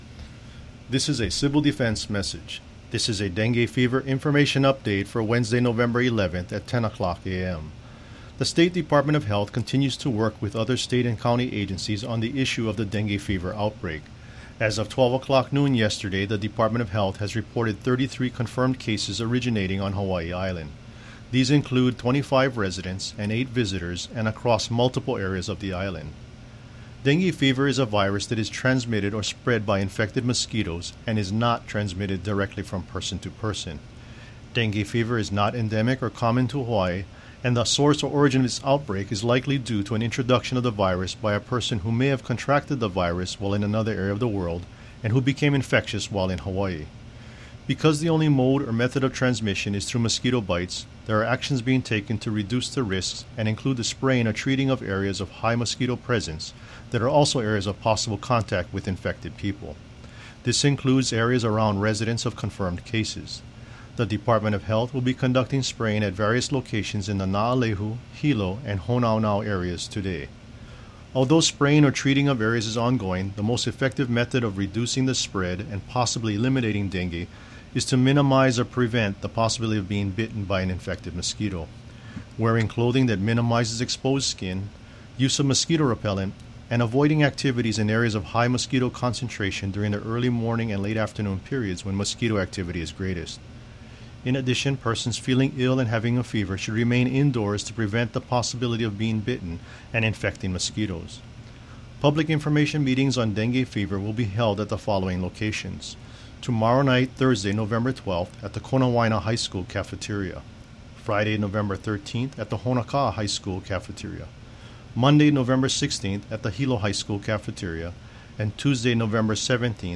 10 a.m. Hawaii County Civil Defense Dengue Fever information update